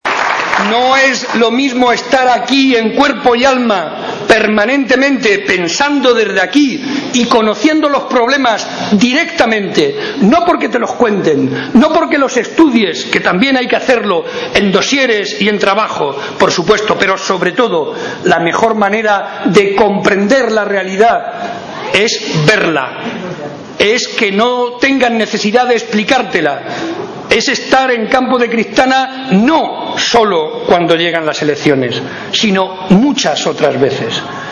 Momento del acto celebrado en Campo de Criptana.